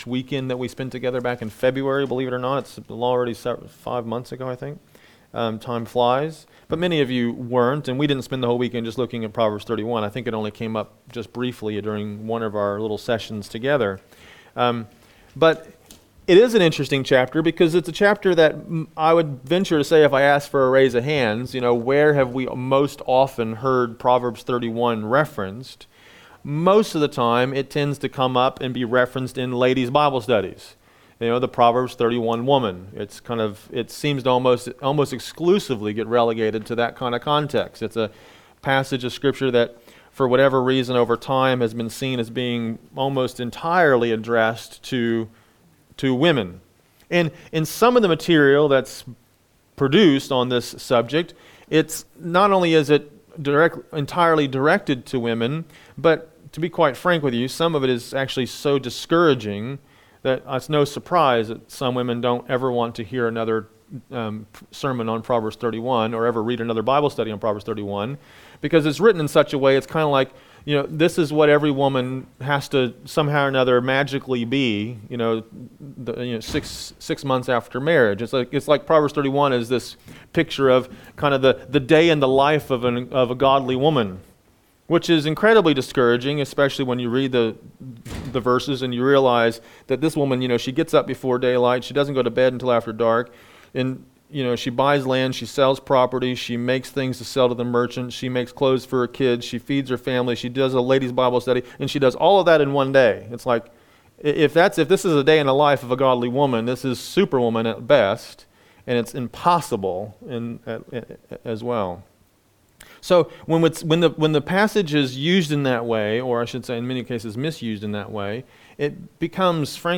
A message from the series "Isaiah."